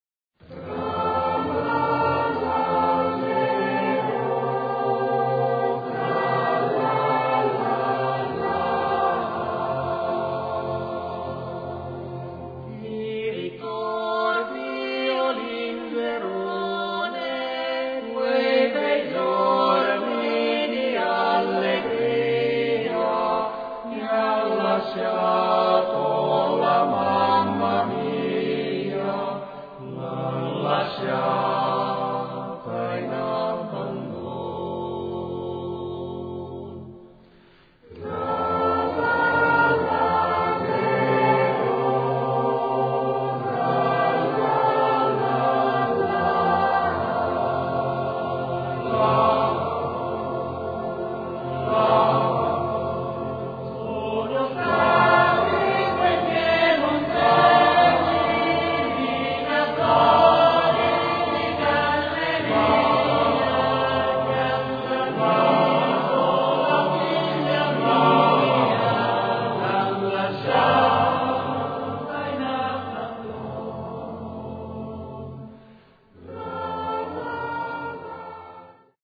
Ricerca, elaborazione, esecuzione di canti popolari emiliani
voci virili